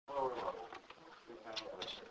Sunbury Business EVP
This EVP was recorded at a business in downtown Sunbury in June of 2008.
The female was there, the male whose voice is heard saying "yes" was not!